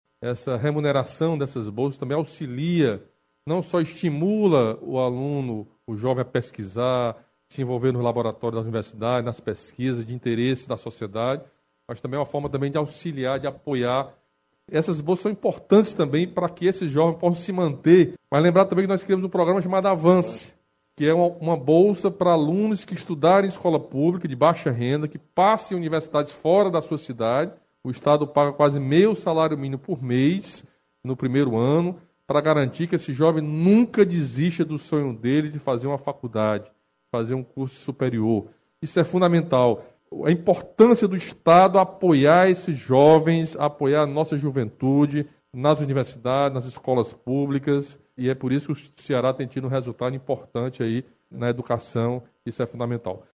O anúncio foi feito pelo governador do Camilo Santana, na manhã desta quarta-feira (04), durante live no Palácio da Abolição.
O governador destacou a bolsa como um estímulo ao estudo, uma prática assertiva já adotada pelo Governo do Ceará.